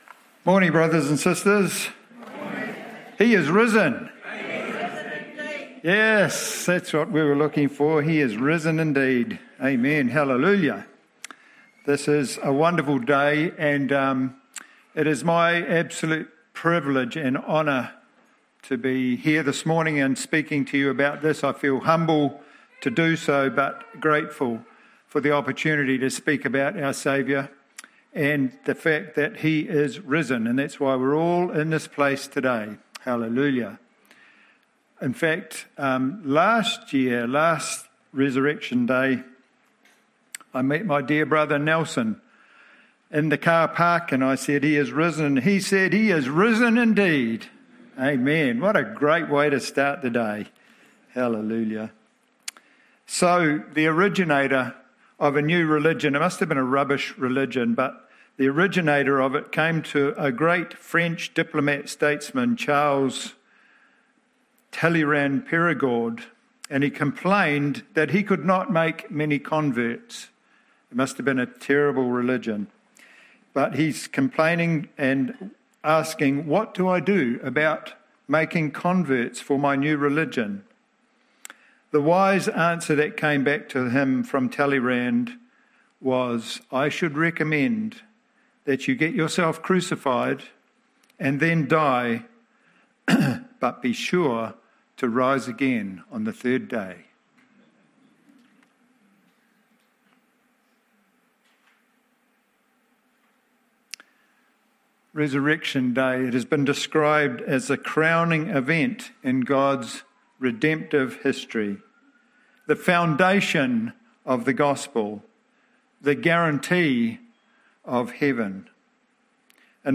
Easter Family Service